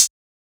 edm-hihat-16.wav